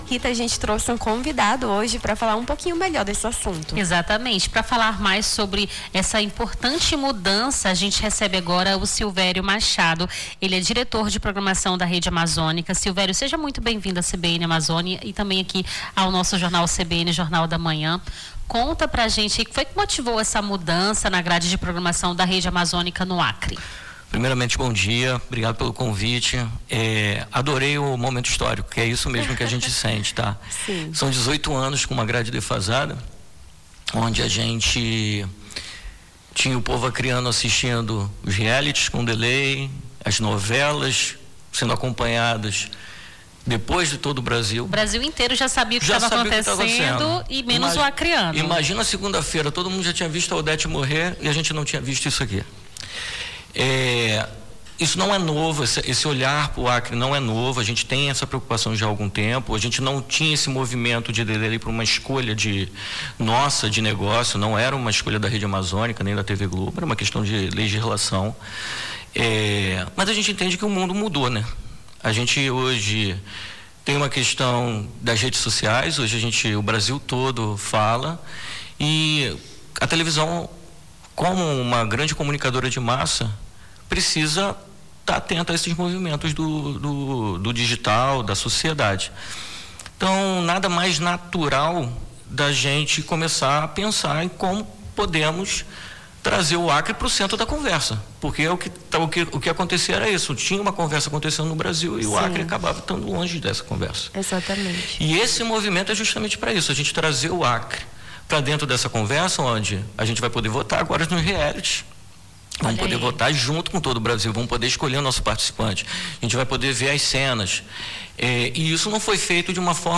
Nome do Artista - CENSURA - ENTREVISTA (NOVA PROGRAMAÇÃO REDE AMAZONICA) 08-10-25.mp3